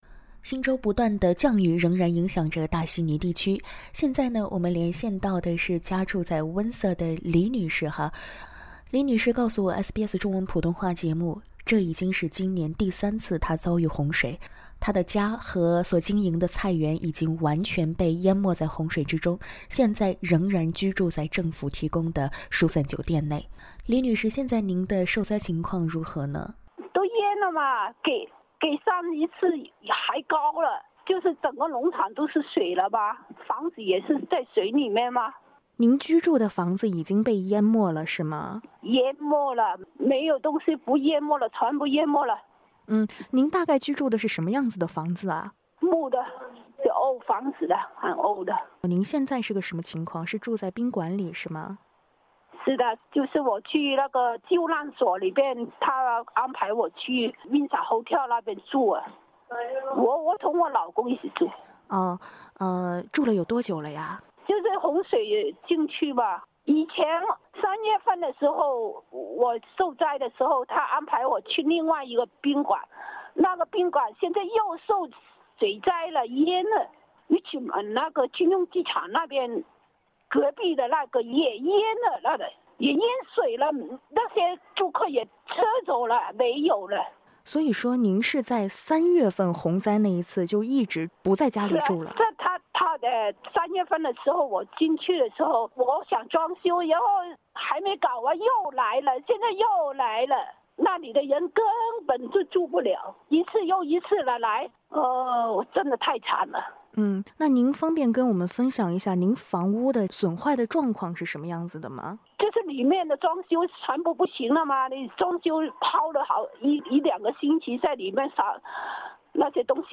本周新州再遇强降雨，多地发出重度洪水警报，受灾华人民众分享了自家的受灾情况。（点击文首图片收听采访）
SBS普通话节目本周《正在行动》热线中，多位华人朋友分享自己的受灾情况，令人揪心。